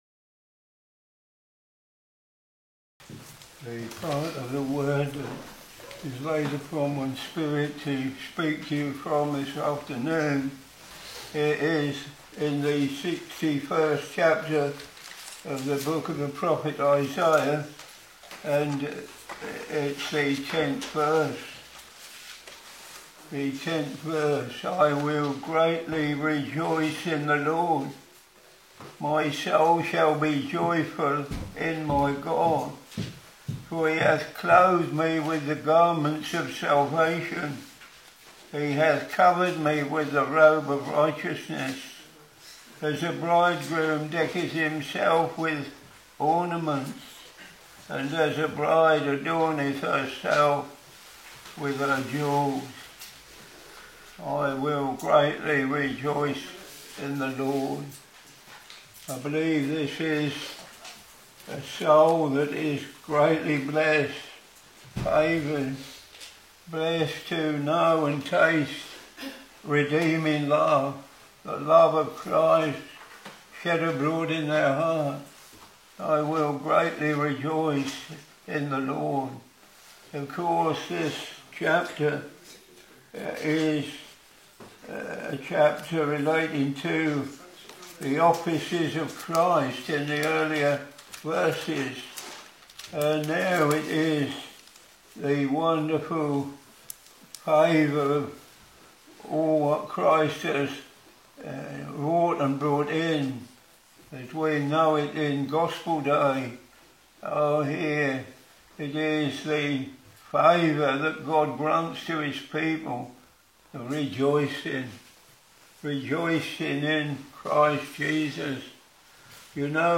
Sermons Isaiah Ch.61 v.10 I will greatly rejoice in the LORD, my soul shall be joyful in my God; for he hath clothed me with the garments of salvation, he hath covered me with the robe of righteousness, as a bridegroom decketh himself with ornaments, and as a bride adorneth herself with her jewels.